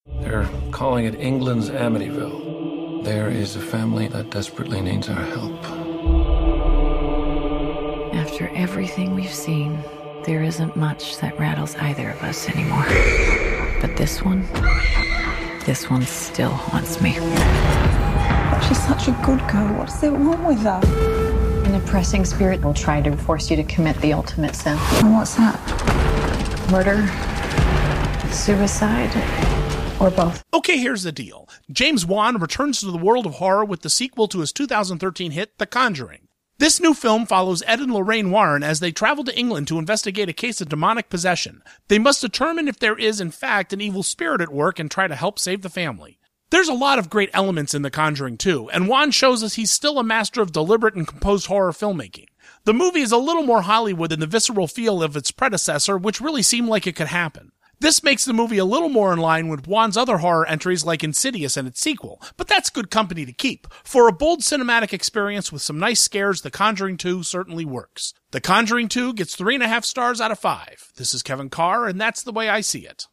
‘The Conjuring 2’ Radio Review